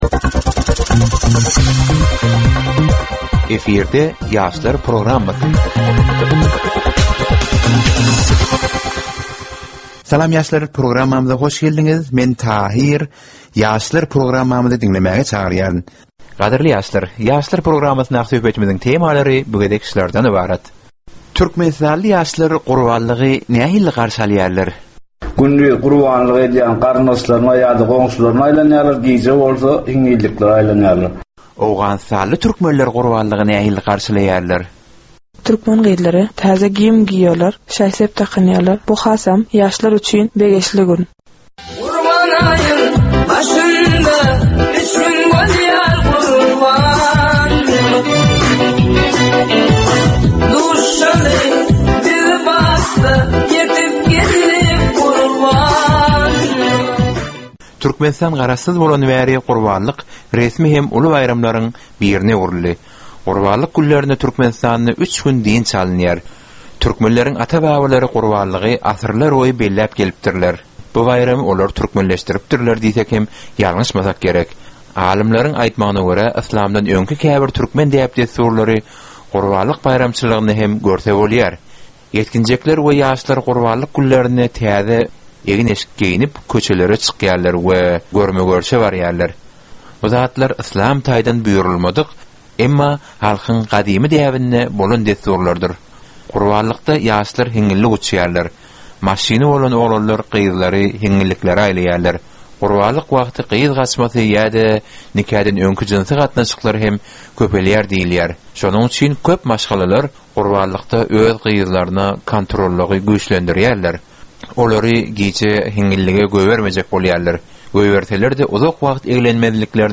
Gepleşigiň dowamynda aýdym-sazlar hem eşitdirilýär.